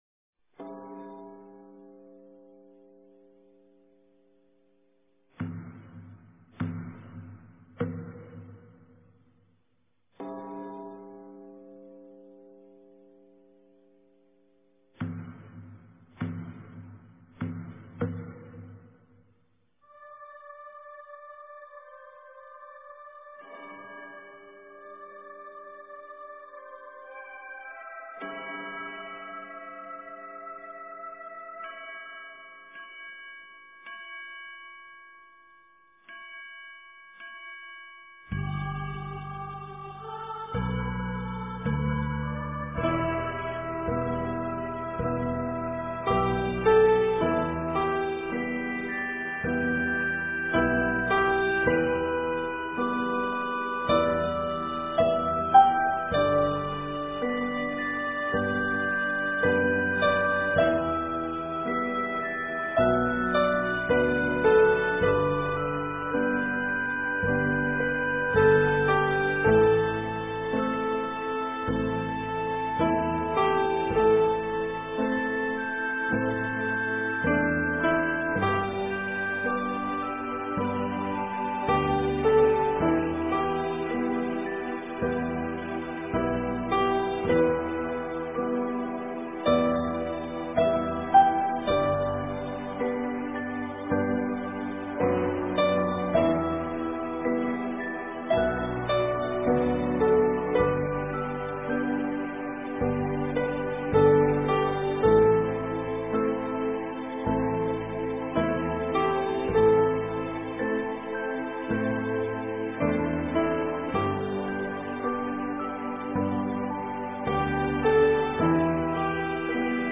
佛音 冥想 佛教音乐 返回列表 上一篇： 锦云--心灵禅修 下一篇： 自然风光(纯音乐